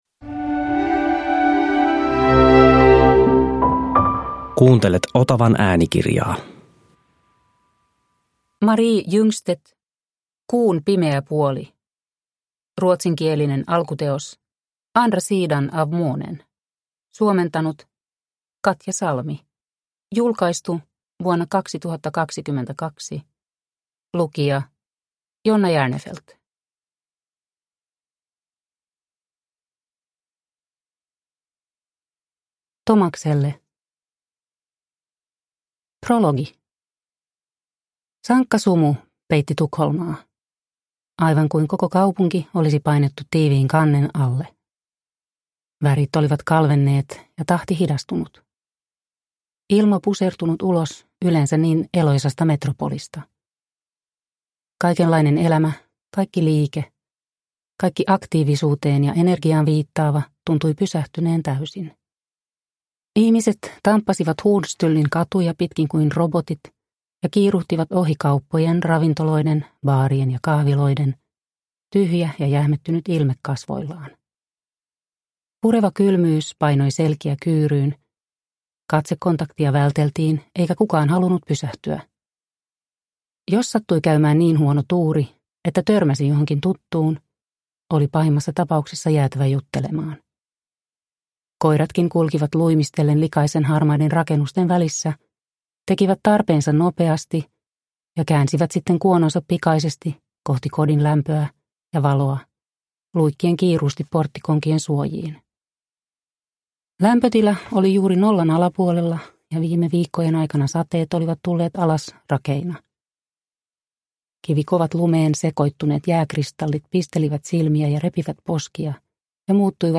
Kuun pimeä puoli – Ljudbok – Laddas ner